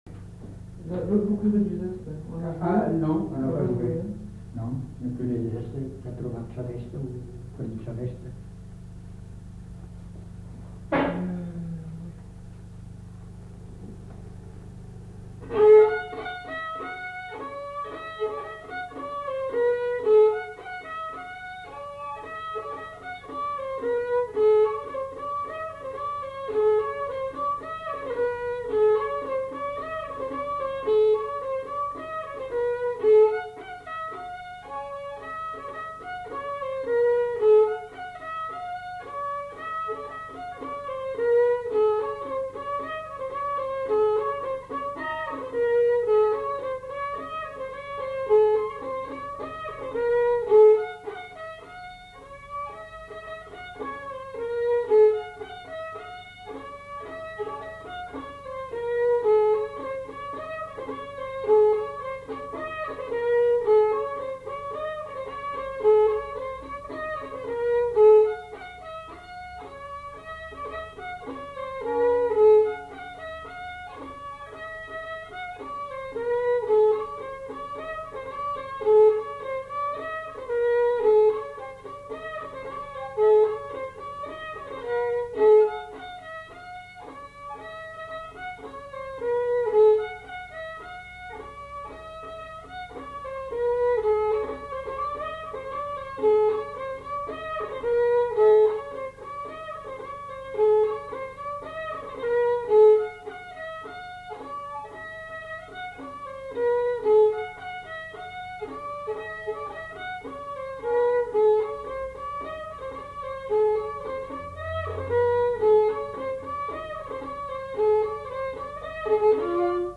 Aire culturelle : Bazadais
Lieu : Bazas
Genre : morceau instrumental
Instrument de musique : violon
Danse : rondeau